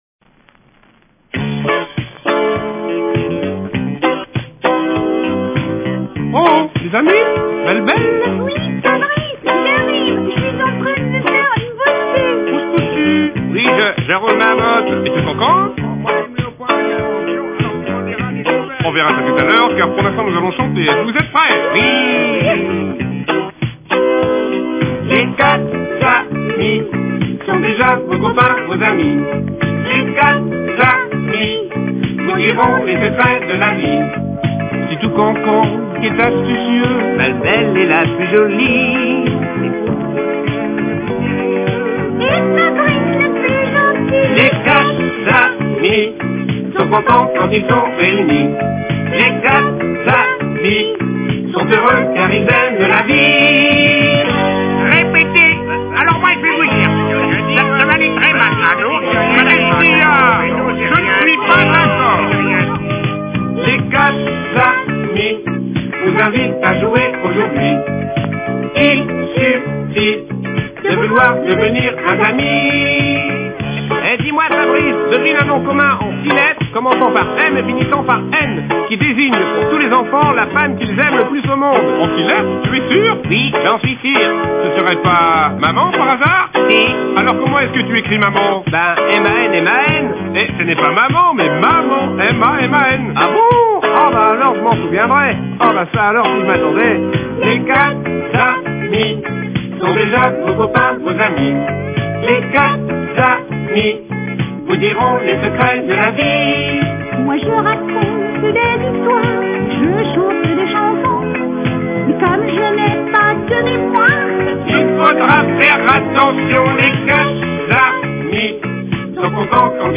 (qualité basse)